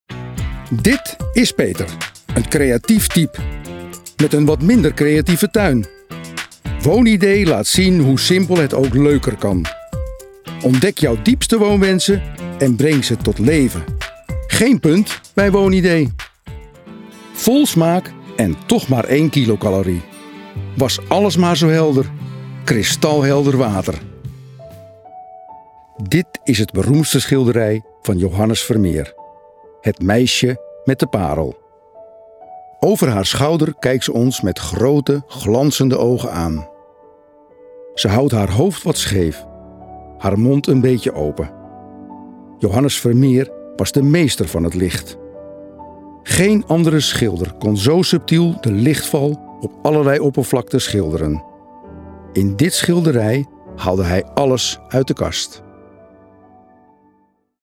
Stemdemo: Link naar ingesproken luisterboeken Presentatie Trainingsacteur Verder werk ik regelmatig als (edel) figurant.